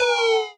Synth(low).wav